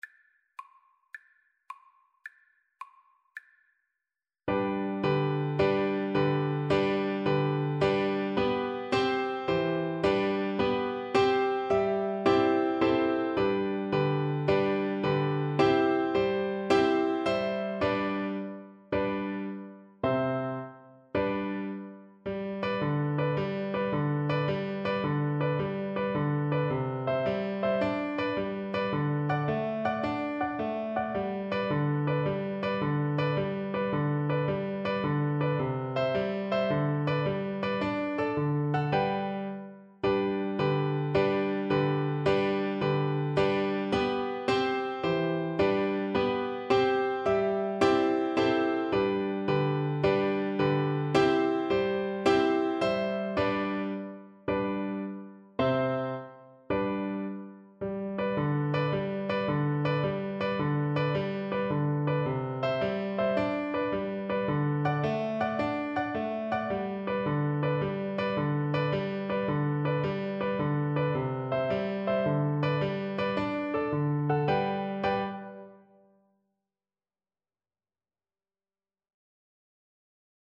Play (or use space bar on your keyboard) Pause Music Playalong - Piano Accompaniment Playalong Band Accompaniment not yet available transpose reset tempo print settings full screen
G major (Sounding Pitch) (View more G major Music for Violin )
6/8 (View more 6/8 Music)
Allegro moderato .=c.108 (View more music marked Allegro)
Traditional (View more Traditional Violin Music)
seven_drunk_nights_VLN_kar1.mp3